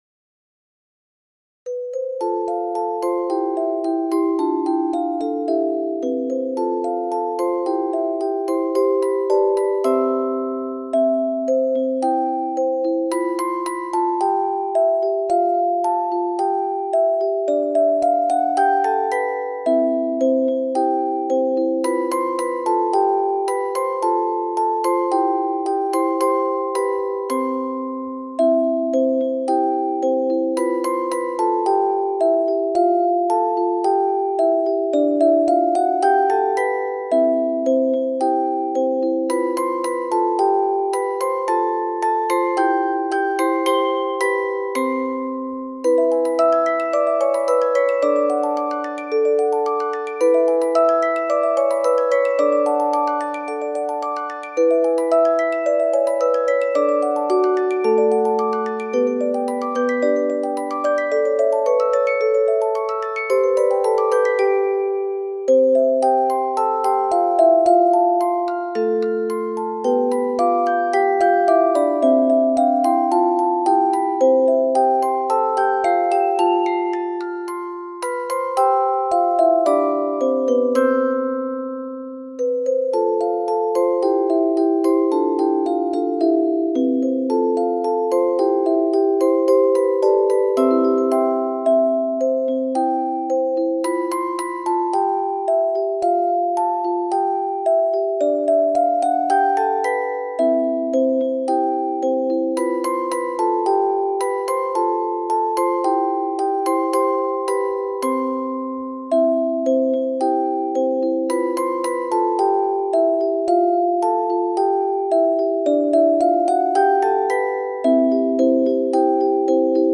それをフルコーラスオルゴールにしてみました。